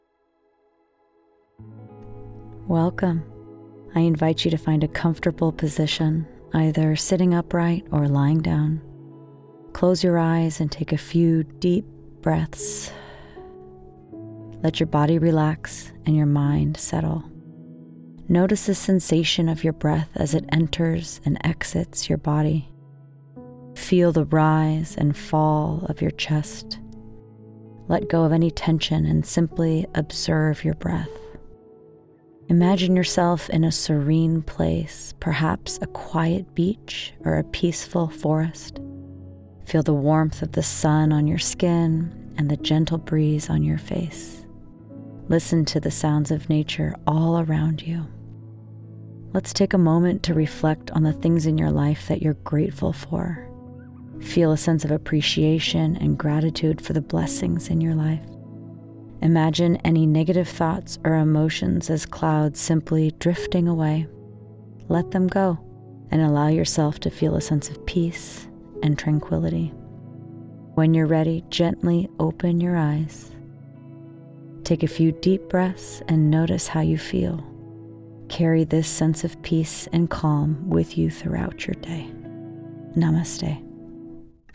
If you're looking for an English American female with an engaging, friendly, warm voice to grab and keep your listener's attention, I'm your gal.
Meditation voiceover demo with music
Meditation Demo w Music.mp3